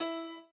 b_piano1_v100l8o6e.ogg